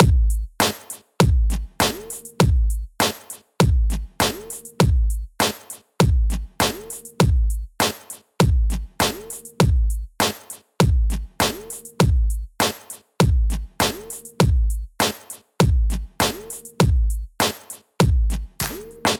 Als Basis für das Beispiel zur parallele Kompression haben wir einen einfachen 8-taktigen Hip-Hop-Beat genutzt.
Um die MP3-Dateien möglichst gut vergleichen zu können, sind alle auf einen RMS-Durchschnittswert von etwa -20 dB eingepegelt.
Einerseits ist bei den nachfolgenden Beispielen zu beobachten, wie sich der Punch trotz hoher Kompression erhält und die Transienten fast unbehelligt bleiben.
Beat mit paralleler Kompression mit UAD LA2A
05_beat_mit_paralleler_kompression_LA2A.mp3